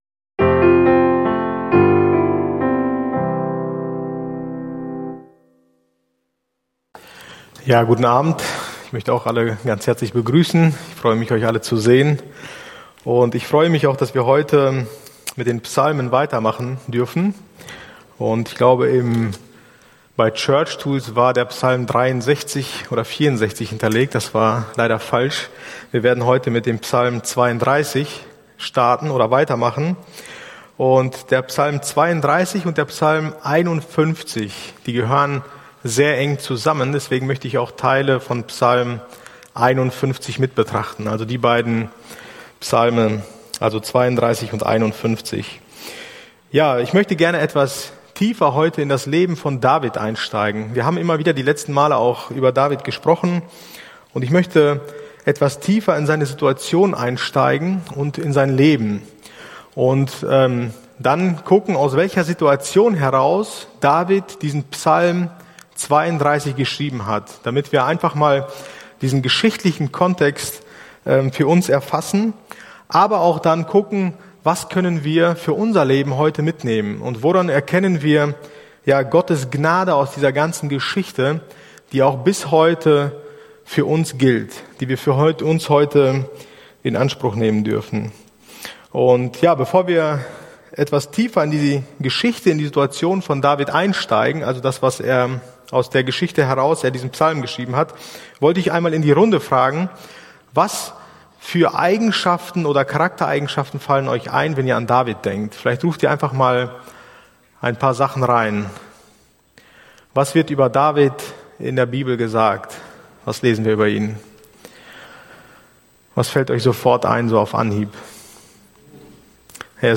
Bibelstunde